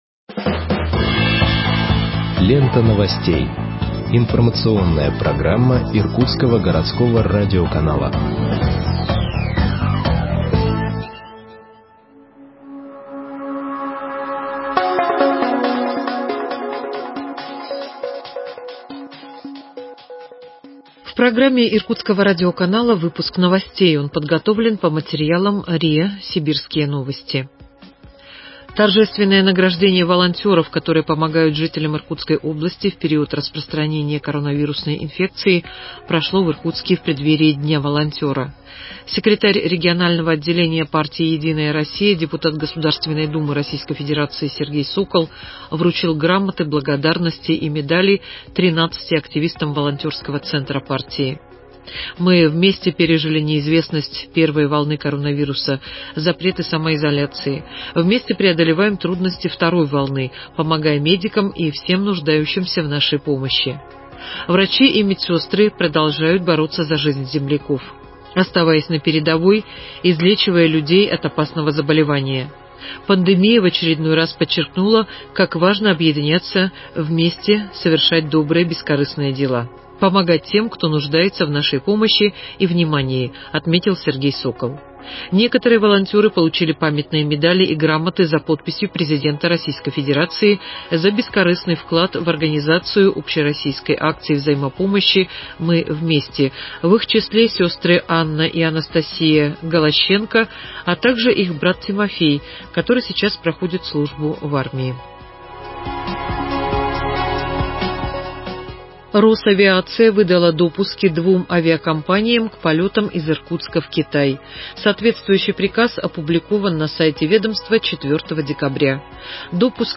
Выпуск новостей в подкастах газеты Иркутск от 09.12.2020 № 1